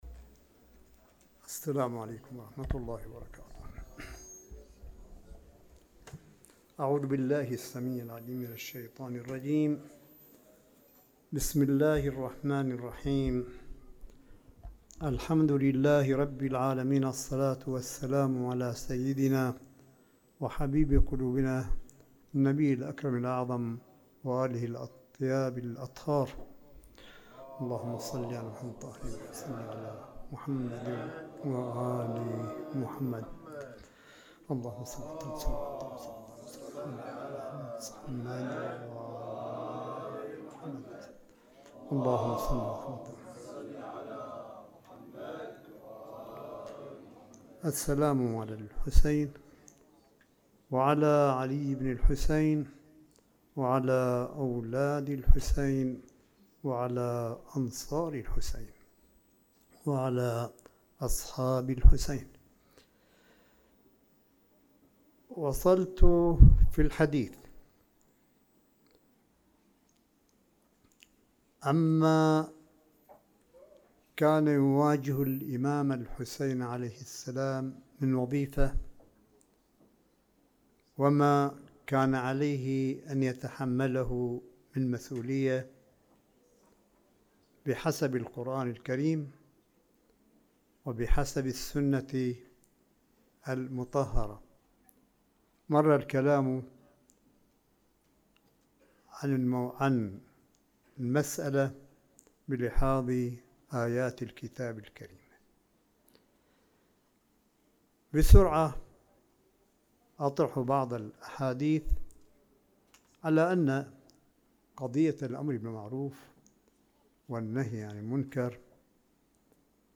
ملف صوتي لكلمة سماحة آية الله الشيخ عيسى أحمد قاسم في موسم عاشوراء ١٤٤١هـ الجزء الثاني مِن (الثورة التي أبقت الإسلام حيًّا) في الحسينية البحرانيّة بـ قم المقدسة – ٢ سبتمبر ٢٠١٩م